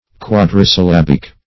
Search Result for " quadrisyllabic" : The Collaborative International Dictionary of English v.0.48: Quadrisyllabic \Quad`ri*syl*lab"ic\, Quadri-syllabical \Quad`ri-syl*lab"ic*al\, Having four syllables; of or pertaining to quadrisyllables; as, a quadrisyllabic word.